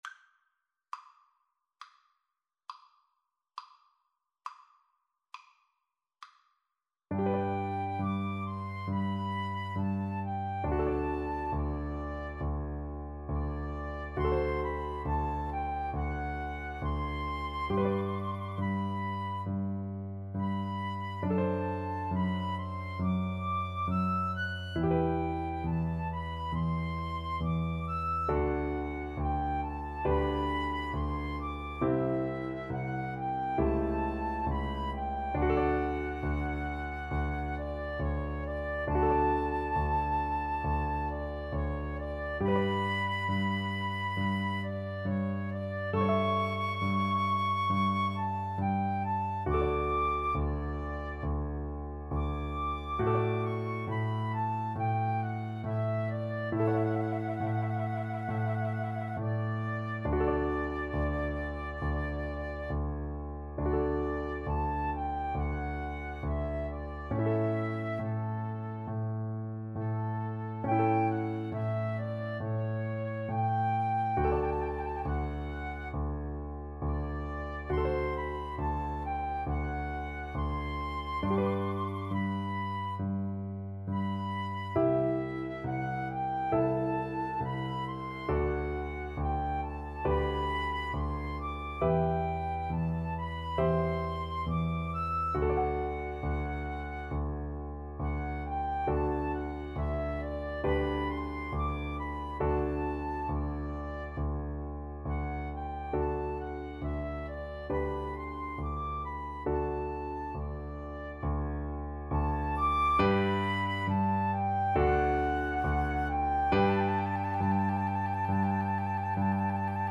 G major (Sounding Pitch) (View more G major Music for Flute-Cello Duet )
= 34 Grave
Classical (View more Classical Flute-Cello Duet Music)